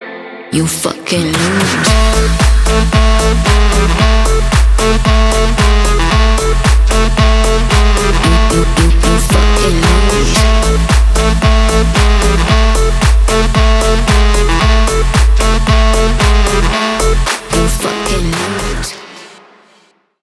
ритмичные
заводные
красивая мелодия
Dance Pop
Саксофон
энергичные
динамичные